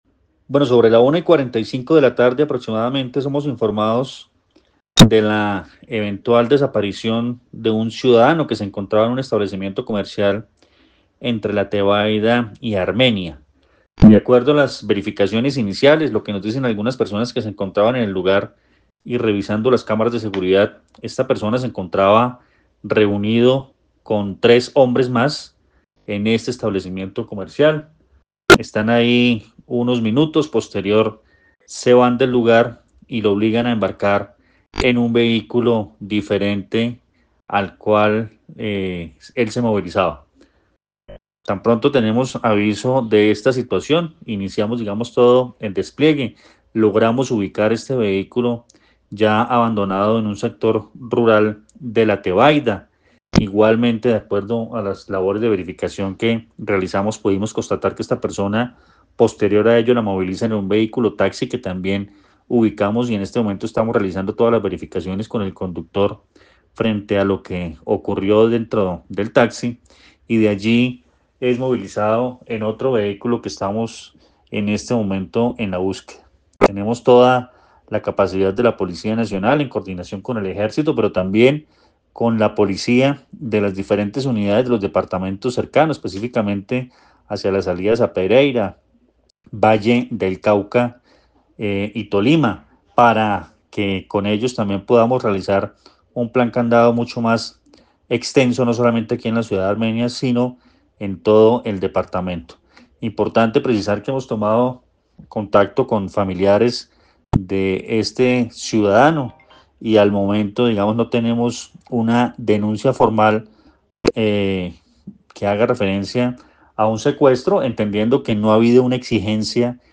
Coronel Luis Fernando Atuesta, comandante Policía, Quindío, presunto secuestro